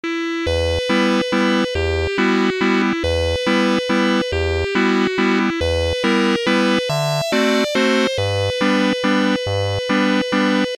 Son d'accordéon dans FL Studio Morphine
Après une rapide mise en forme, voici de façon très brute le type de son qu'on peut obtenir : 🎧
vst_morphine_demo.mp3